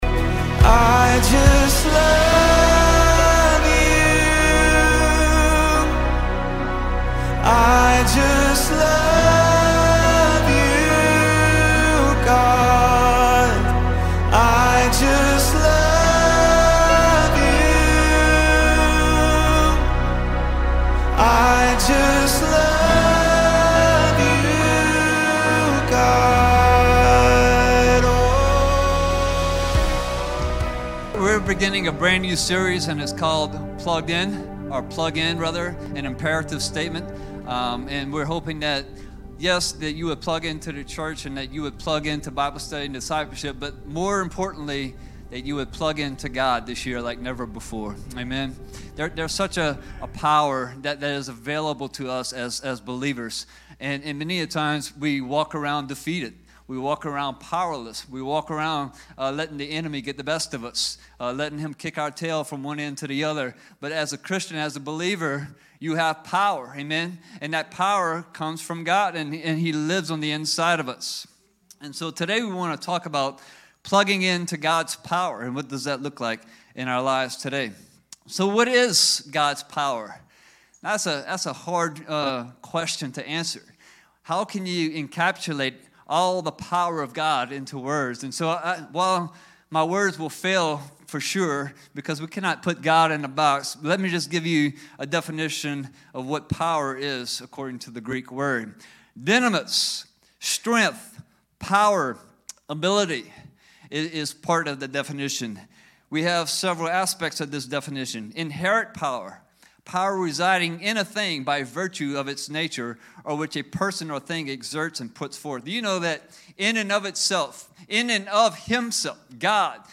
Sermons | Hope Community Church